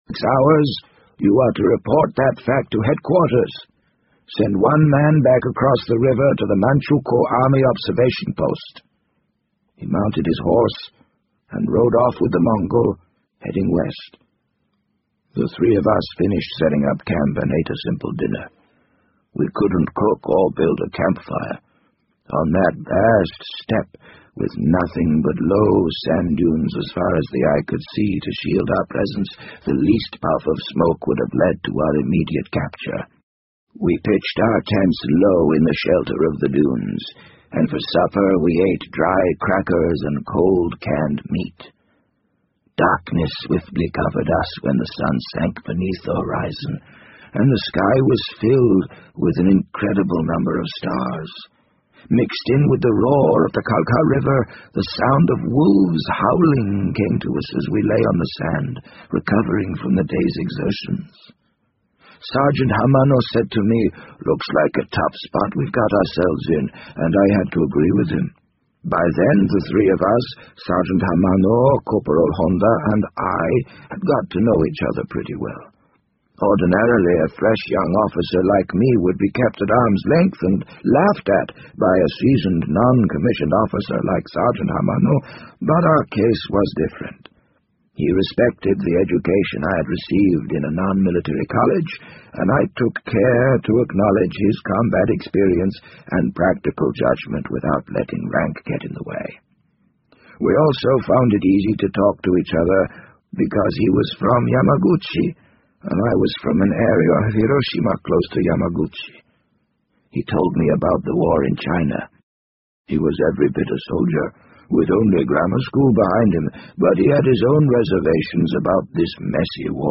BBC英文广播剧在线听 The Wind Up Bird 004 - 8 听力文件下载—在线英语听力室